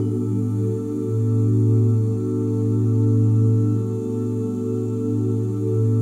OOH A MIN9.wav